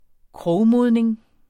Udtale [ ˈkʁɔwˌmoðneŋ ]